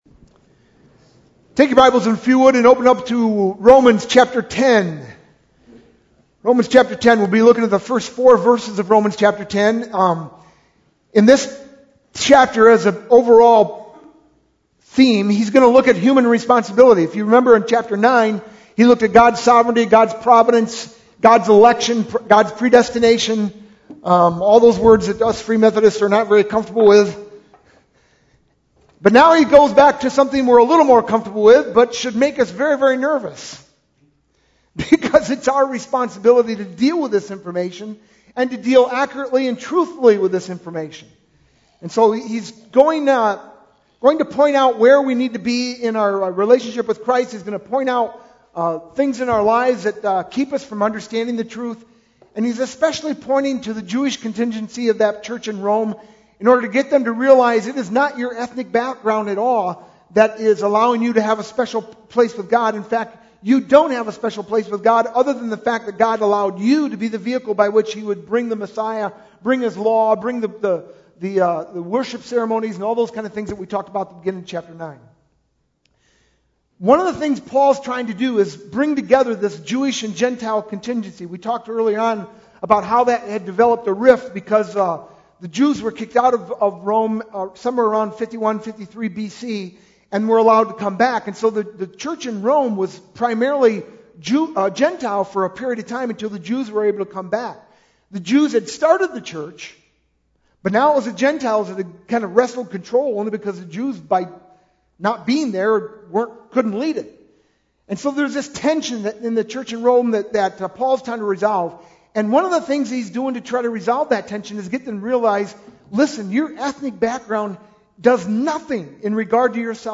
sermon-9-4-11.mp3